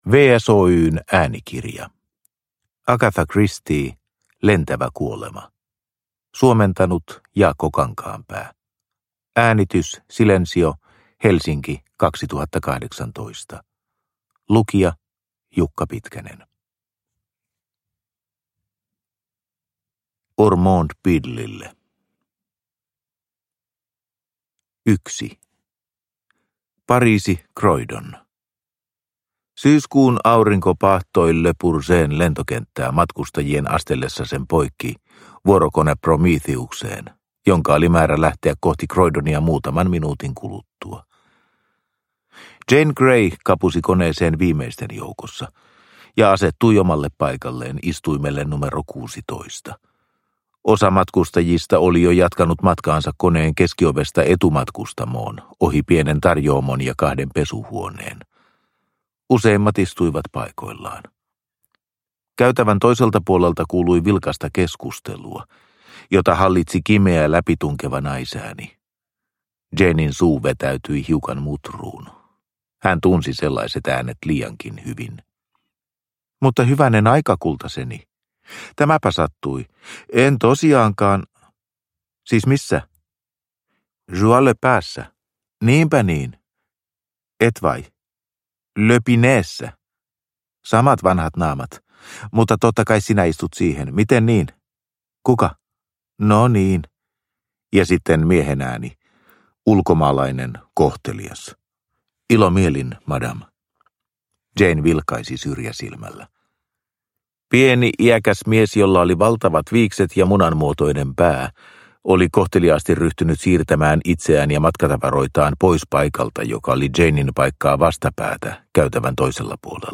Lentävä kuolema (ljudbok) av Agatha Christie